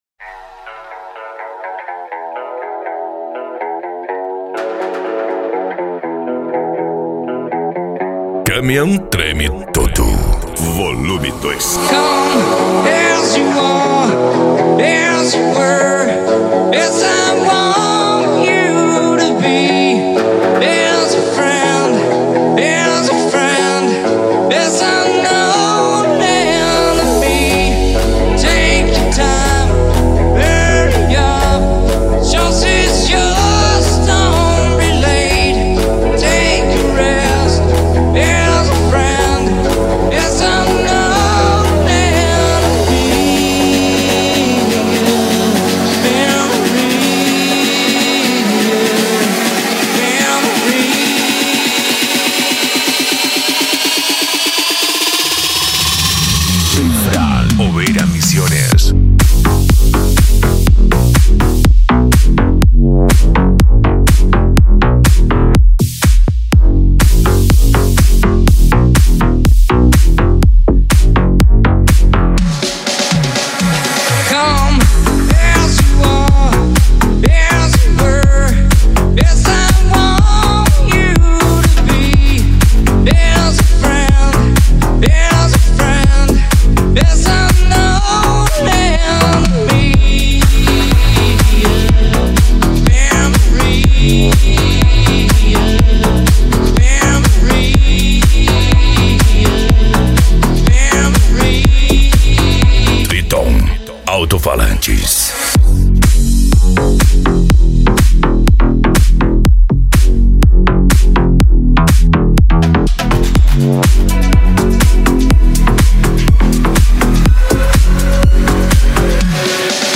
Modao
PANCADÃO
Remix